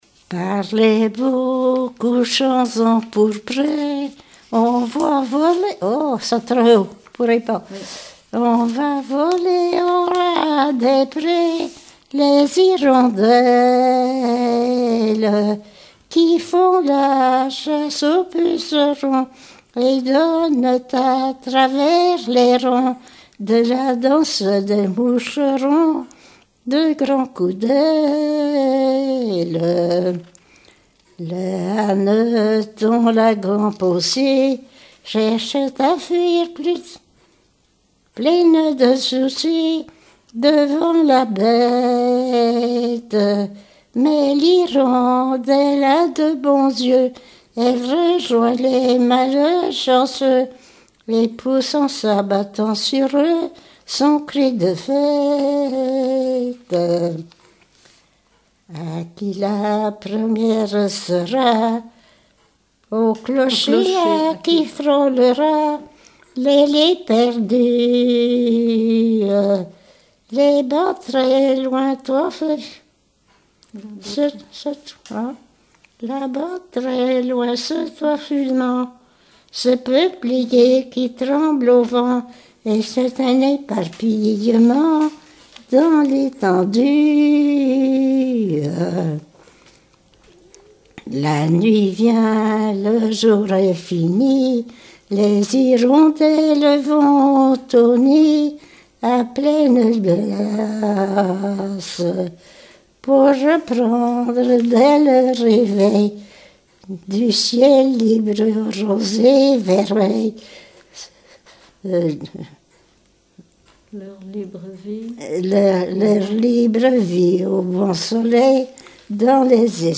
Genre strophique
chansons d'écoles et populaires
Pièce musicale inédite